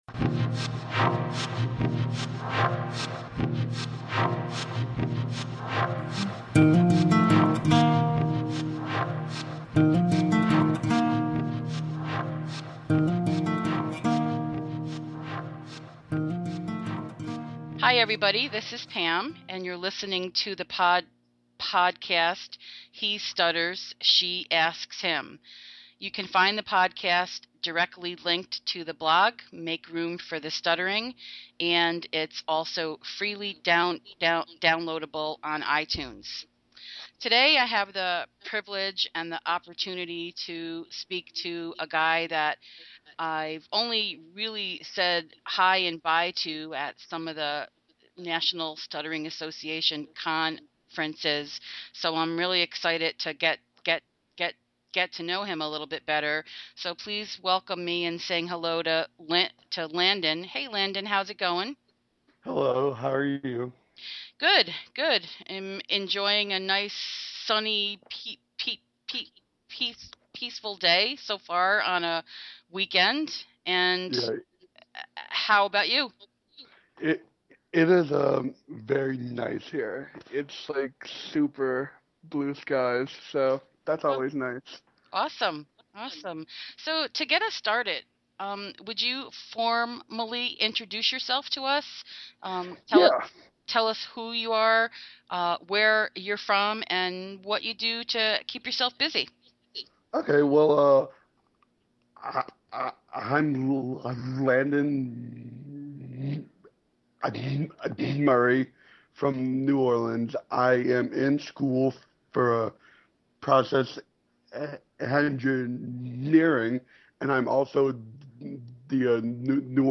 (Producer note: There was background noise/echo in this track that I could not edit.